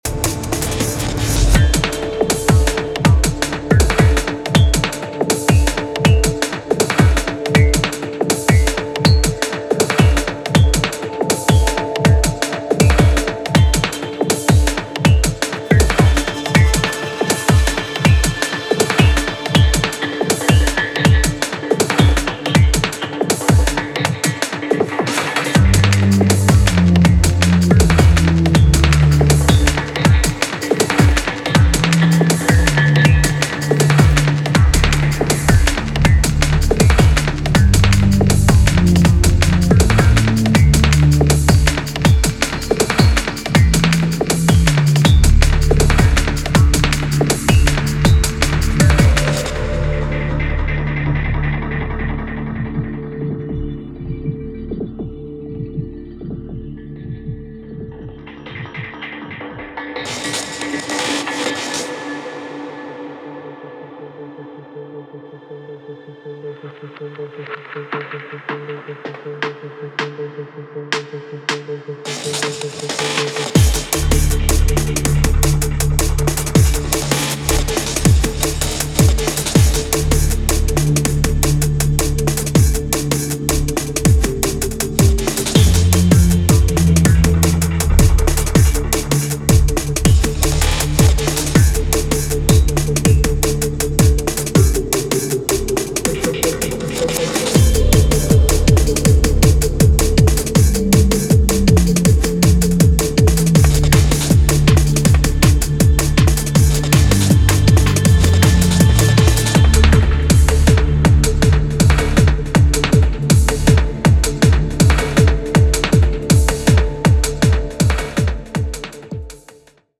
halftime jungle-IDM stylings
in a haze of fractured breaks and dubbed-out atmospherics
House Breaks Trance